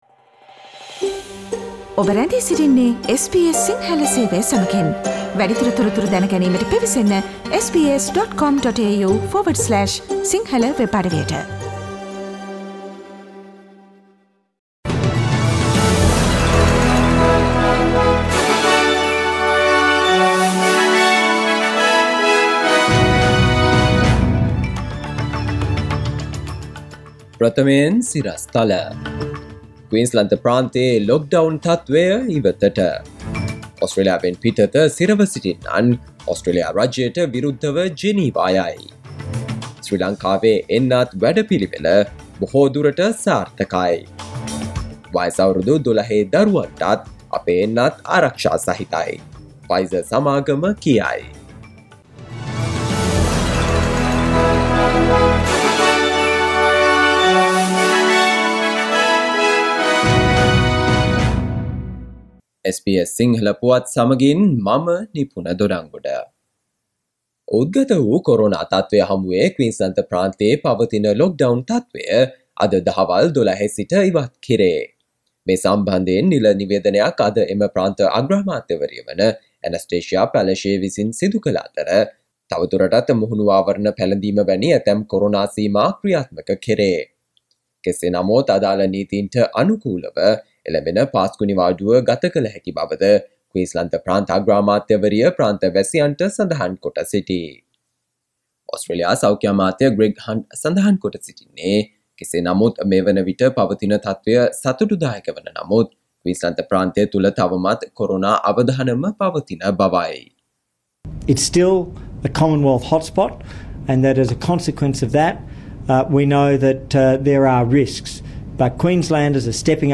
Here are the most prominent Australian, Sri Lankan, International, and Sports news highlights from SBS Sinhala radio daily news bulletin on Thursday 1 April 2021.